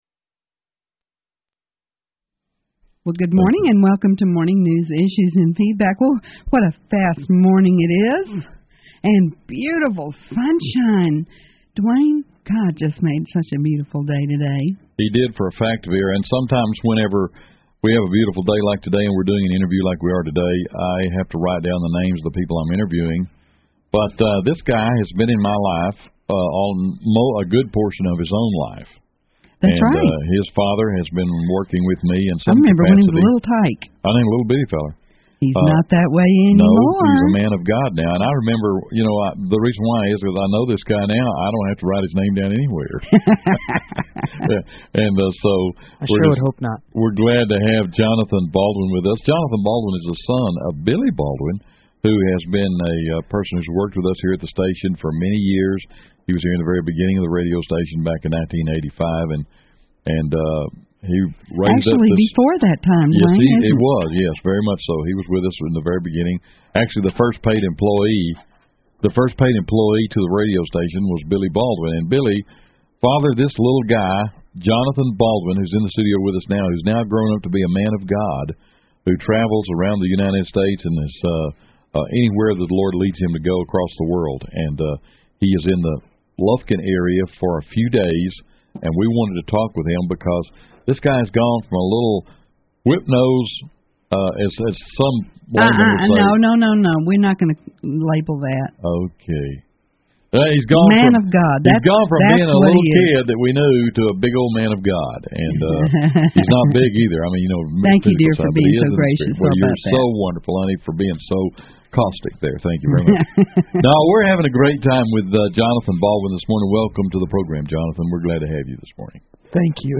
Radio Interview About the International House of Prayer Kansas City
In February of 2007 I was a guest on 91.9 FM KAVX’s Morning Show. In the interview I covered various subjects from how we do prayer at IHOPKC to the need for night and day prayer all over the earth. We also talked about the nature of intercession and a particular focus of prayer for the ending of abortion in Lufkin, TX.
Radio-Interview-IHOPKC-Morning-News-Issues-and-Feedback.mp3